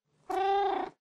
purreow1.ogg